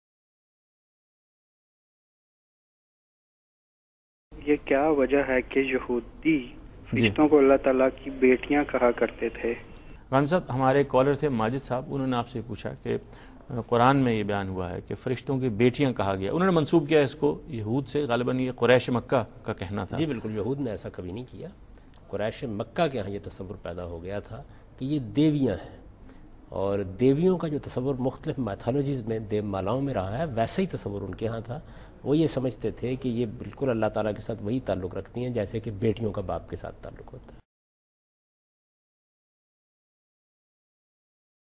Category: TV Programs / Dunya News / Deen-o-Daanish /
Javed Ahmad Ghamidi answers a question "Are angels Daughters of God?" in program Deen o Daanish on dunya News.